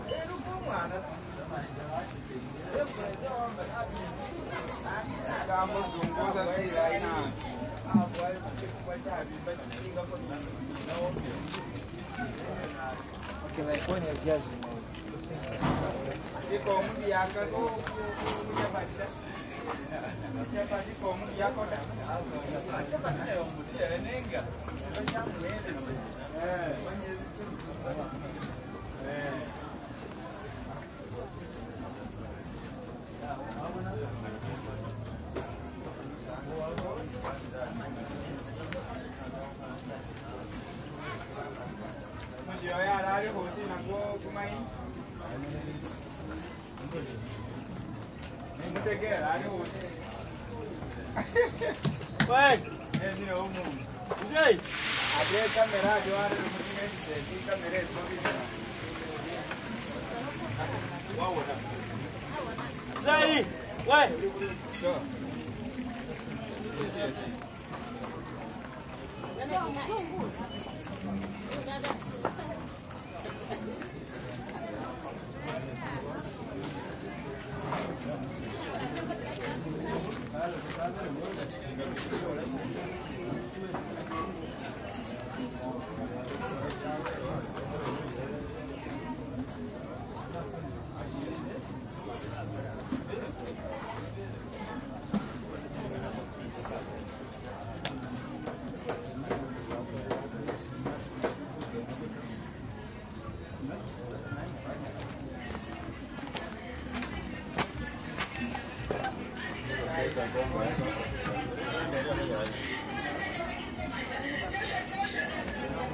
油炸烹饪
描述：油炸烹饪，炒锅中的铁板烧油
标签： 火热 油炸 烹饪